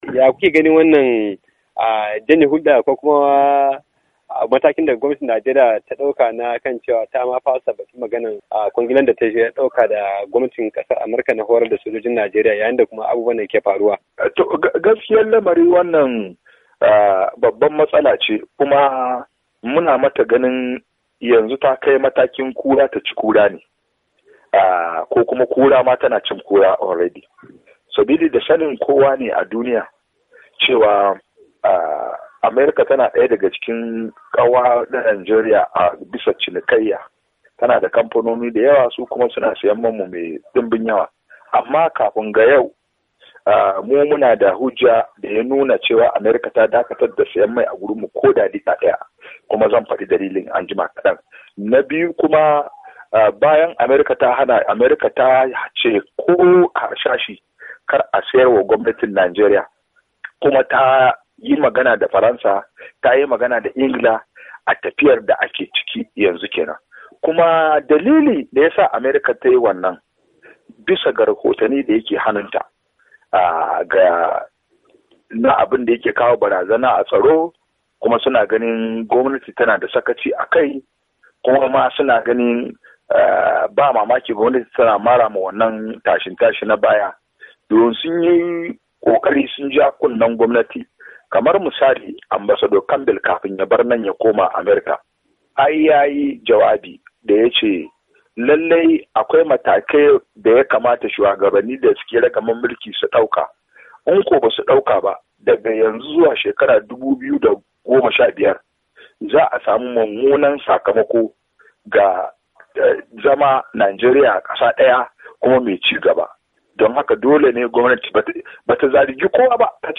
To amma Muryar Amurka tayi firar da wani masani akan harkokin tsaro.